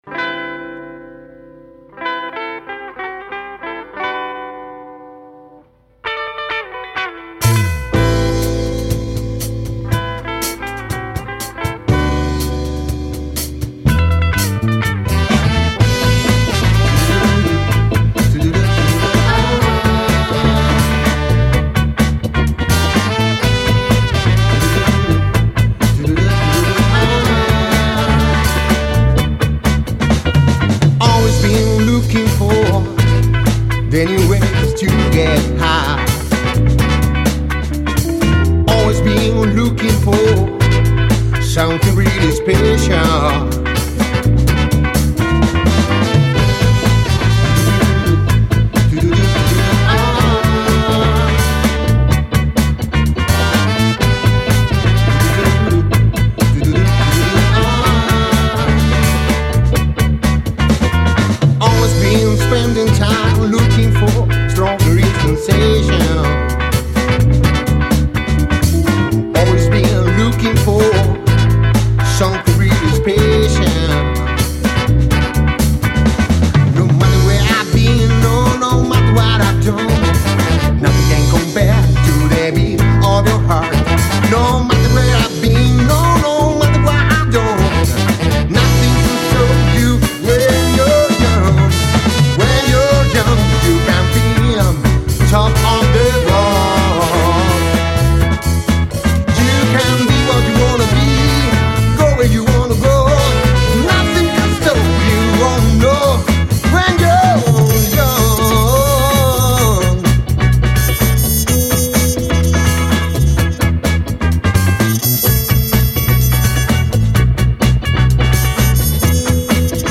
northern soul